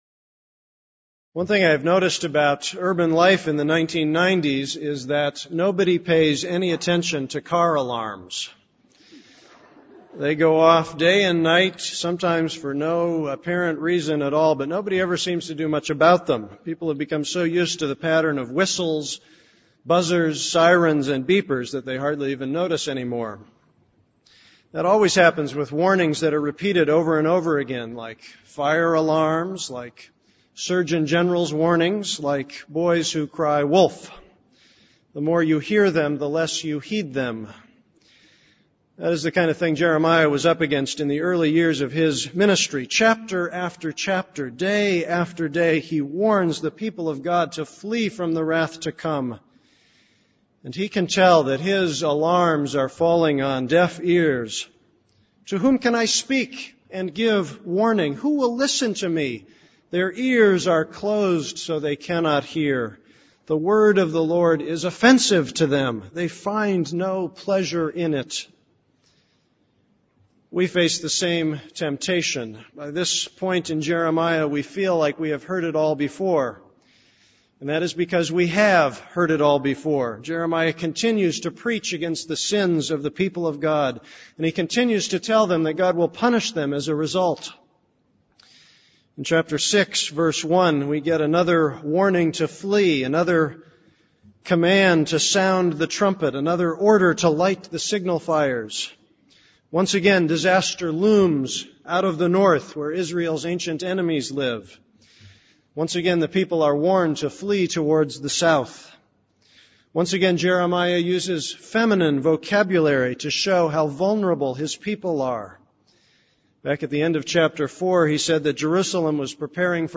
This is a sermon on Jeremiah 5:1-19.